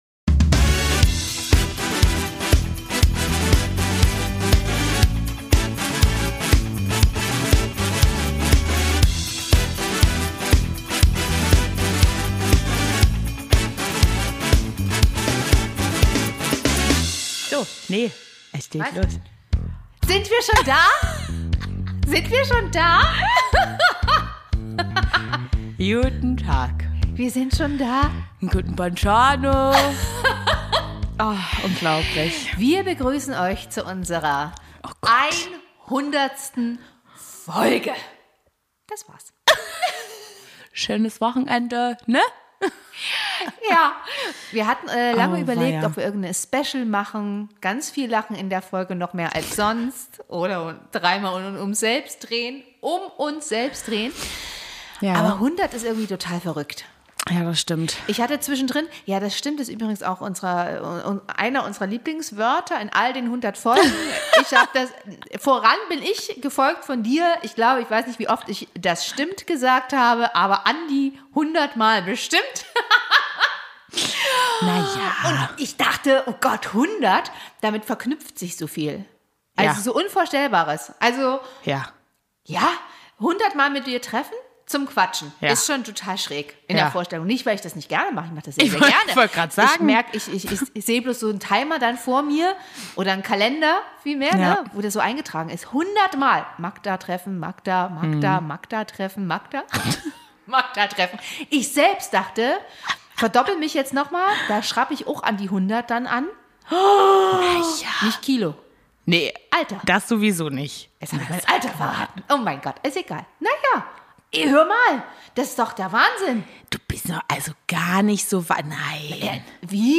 Es ist und bleibt für uns der beste Mutter-Tochter Podcast!